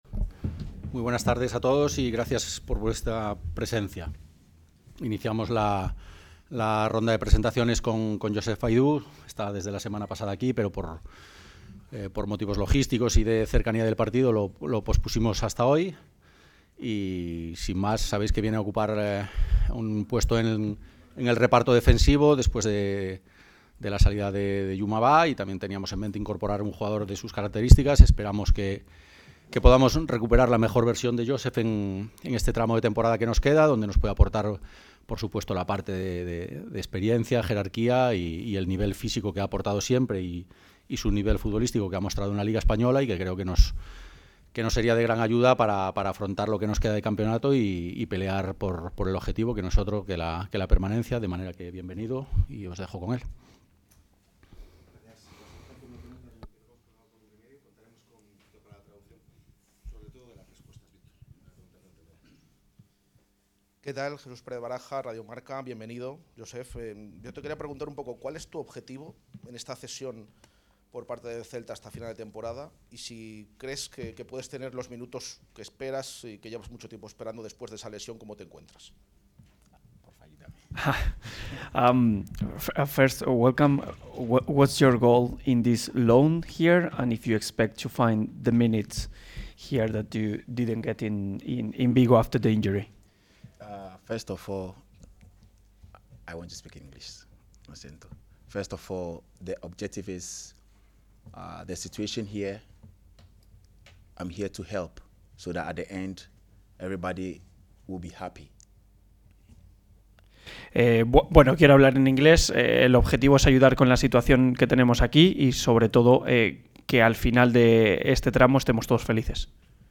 Ruedas de prensa
La sala de prensa del Estadio José Zorrilla albergó en la mañana de este miércoles la cuádruple presentación de los últimos refuerzos del Real Valladolid en el mercado invernal.